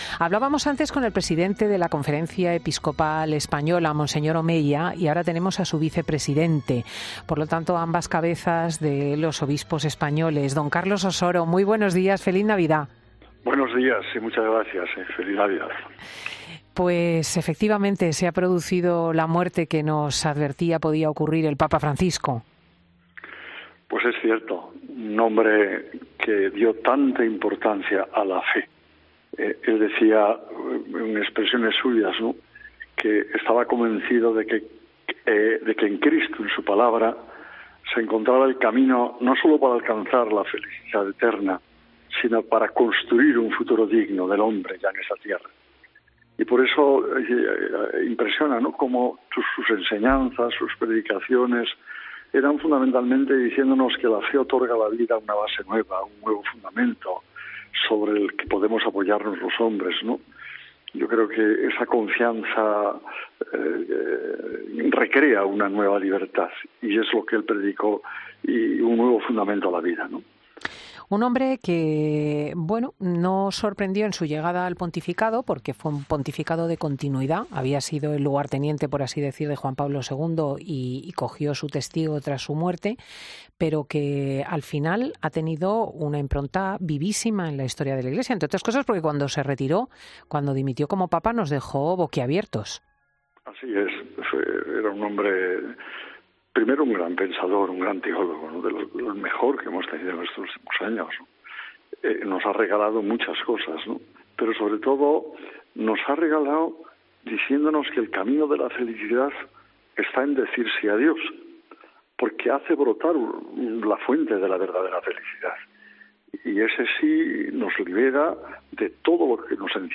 El arzobispo de Madrid destaca en 'Fin de Semana' el carácter amable y la capacidad de escucha especial del Papa emérito: "Transmitía una inmensa confianza"